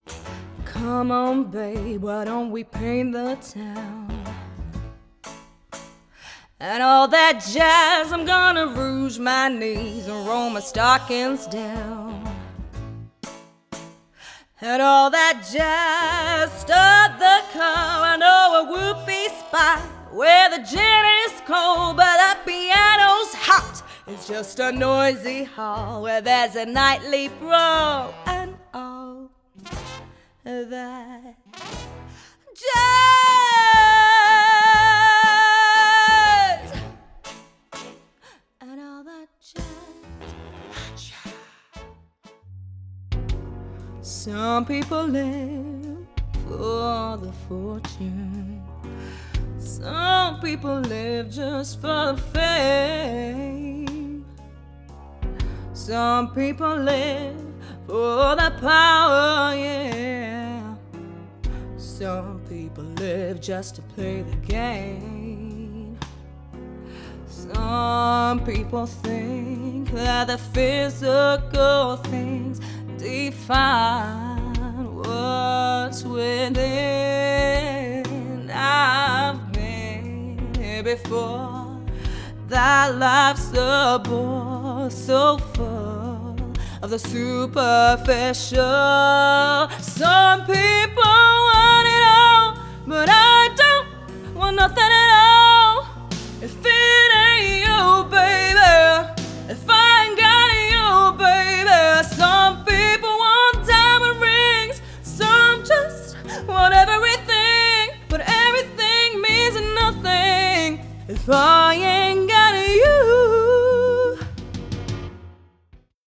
Singing Showreel
Female
London
Neutral British
Confident
Cool
Down To Earth
Friendly